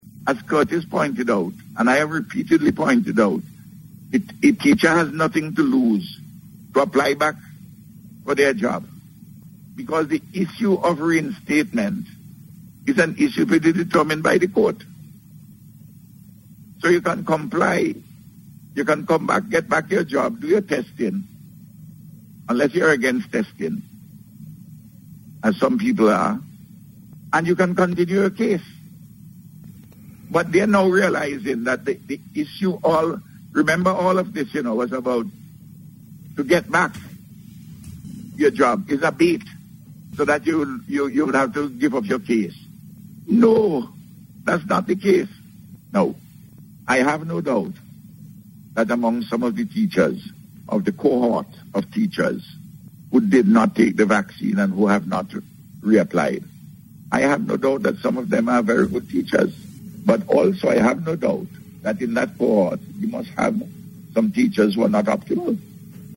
Meanwhile, Prime Minister, Dr. Ralph Gonsalves again addressed the issue regarding the re-employment of unvaccinated Teachers, while speaking on Radio yesterday.